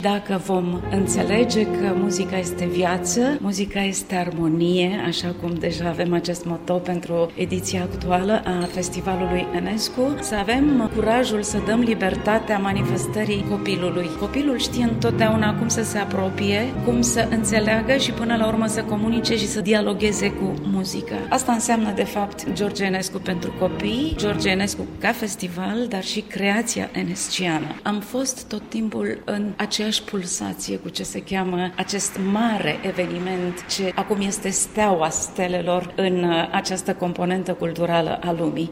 Şi Opera Comică pentru Copii a pregătit 12 spectacole speciale, după cum aflăm de la managerul instituţiei, soprana Felicia Filip: